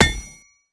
Axe Impact.wav